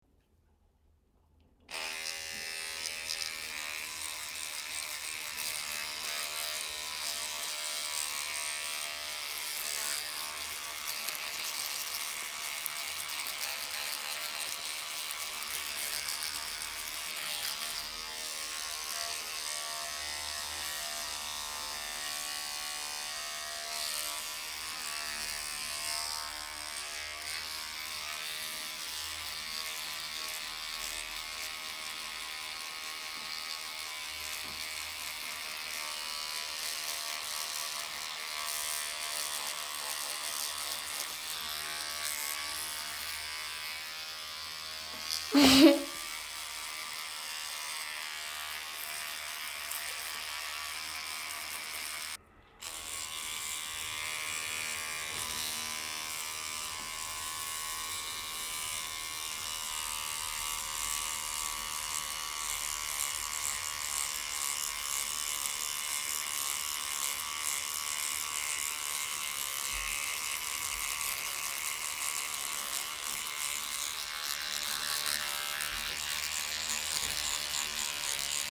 Звук чистки зубов скачать
Скачать звуки чистки зубов зубной щеткой и слушать онлайн.